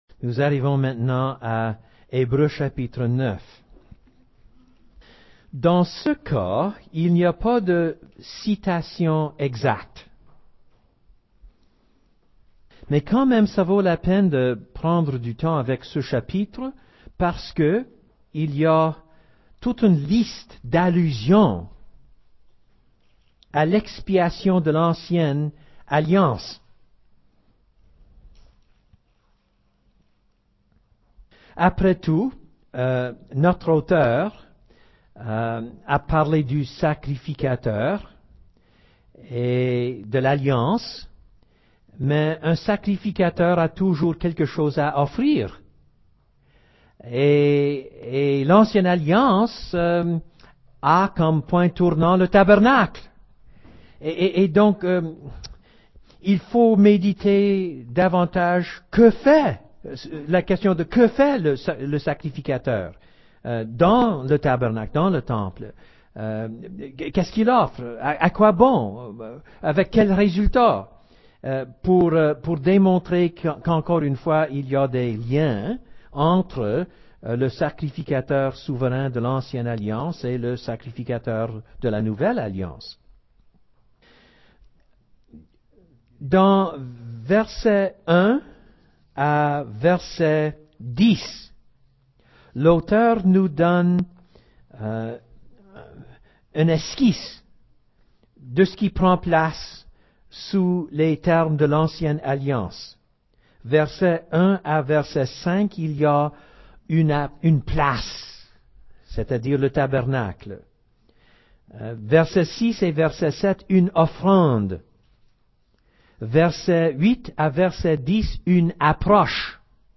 Type De Service: Culte Dimanche